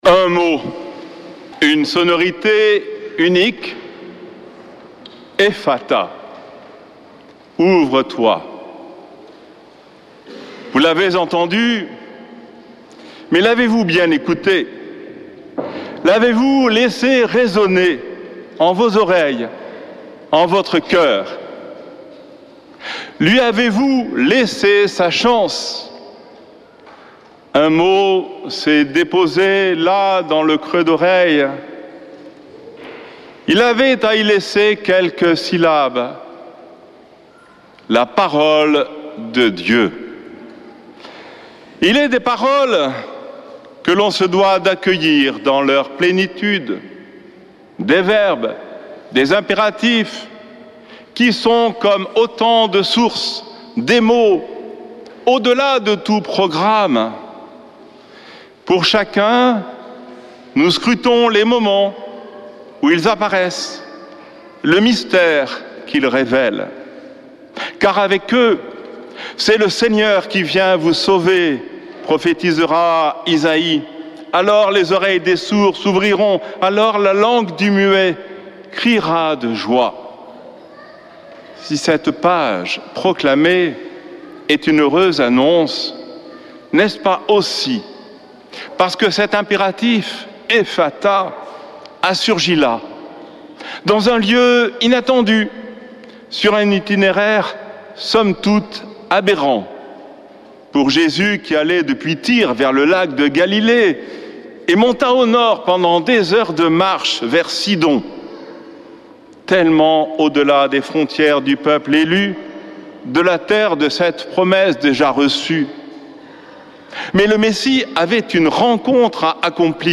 Accueil \ Emissions \ Foi \ Prière et Célébration \ Messe depuis le couvent des Dominicains de Toulouse \ Effata, Ouvre-toi…
Homélie du 8 septembre